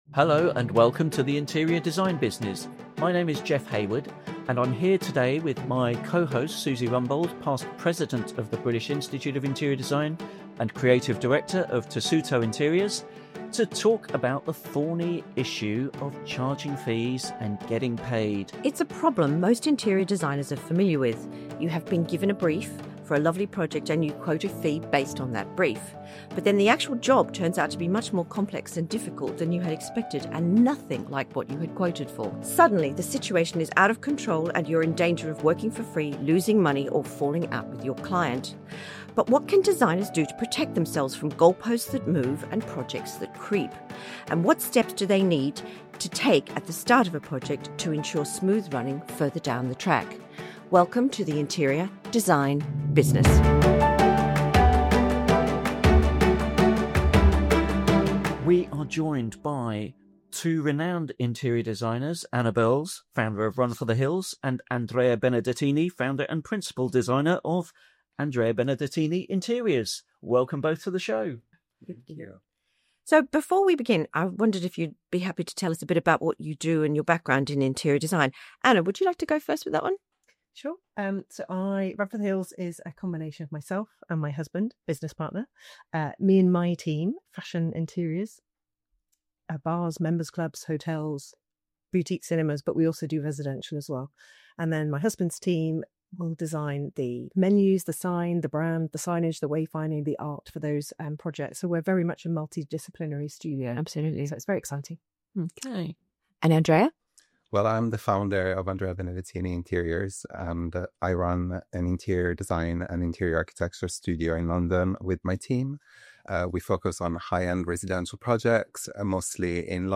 This episode was recorded at the London office of Run For The Hills